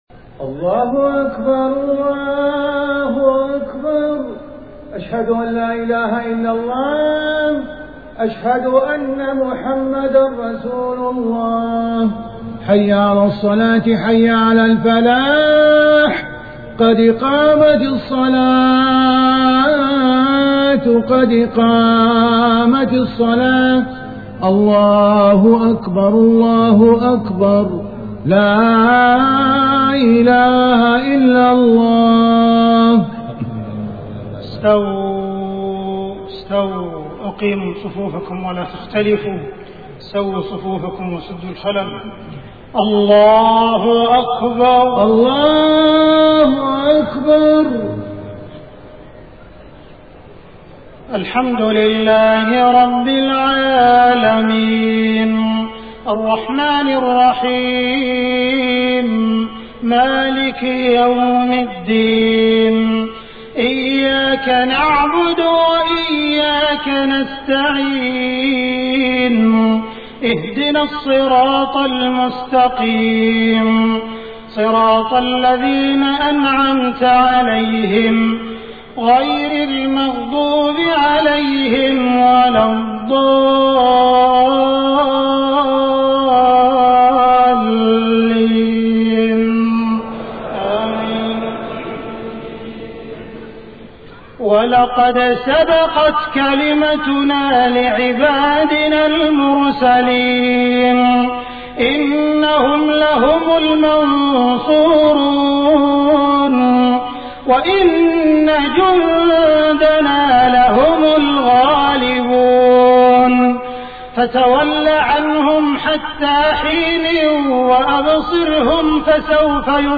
صلاة الجمعة 28 صفر 1431هـ خواتيم سورة الصافات 171-182 و النصر > 1431 🕋 > الفروض - تلاوات الحرمين